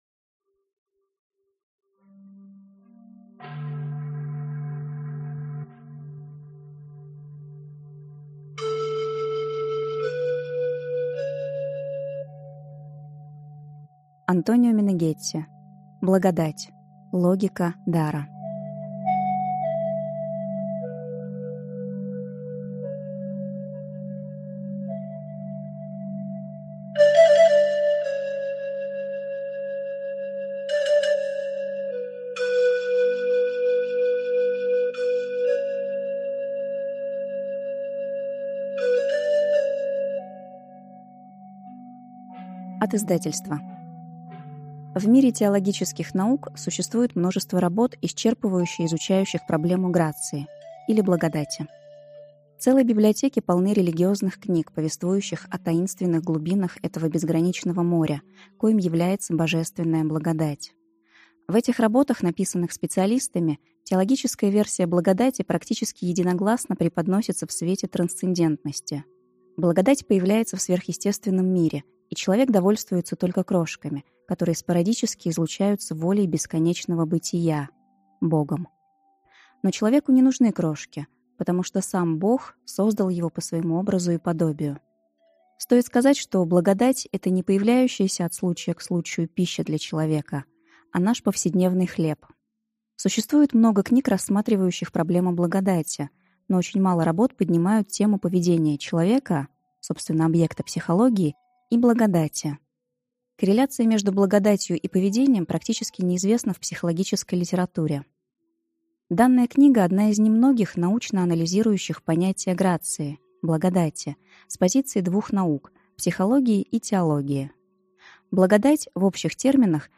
Аудиокнига Благодать как логика дара | Библиотека аудиокниг